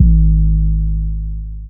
Lex 808.wav